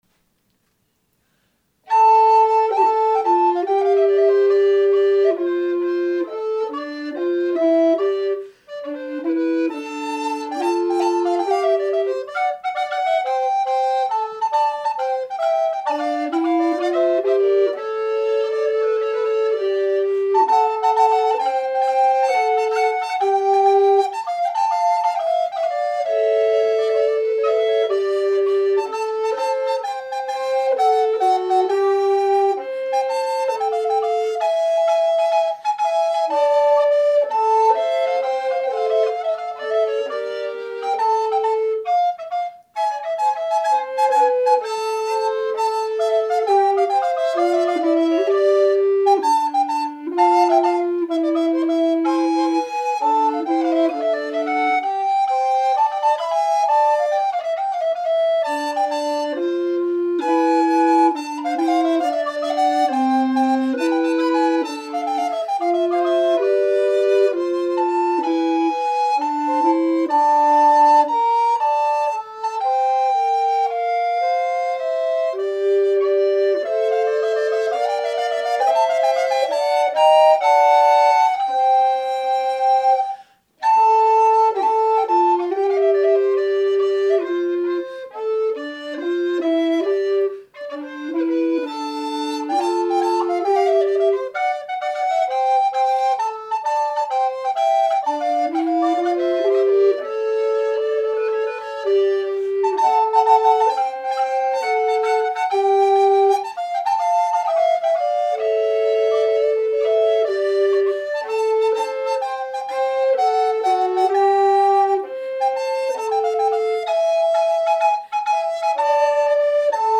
From the 5 November 2006 concert, La Caccia